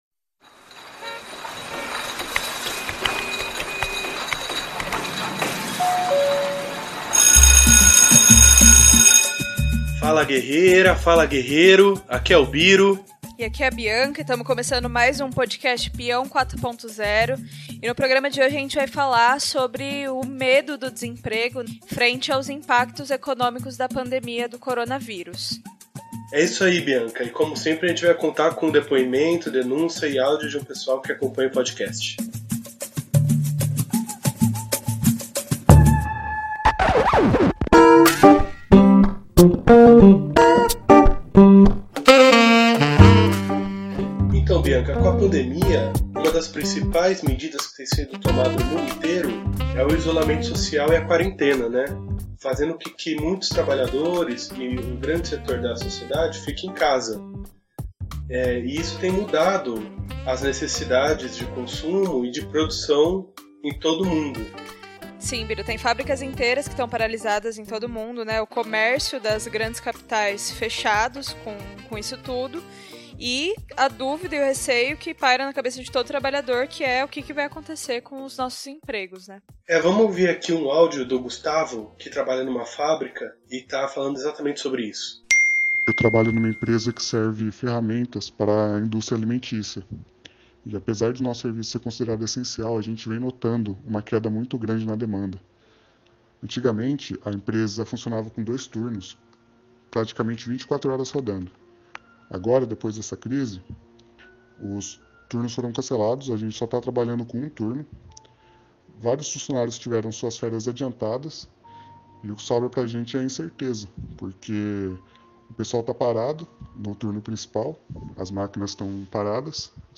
O Peão 4.0 traz nesse episódio os relatos de trabalhadores de fábricas, professores, trabalhadores de transporte rodoviário, aeroviário e metroviário e outros serviços sobre as demissões que colocam nas ruas milhares de trabalhadores.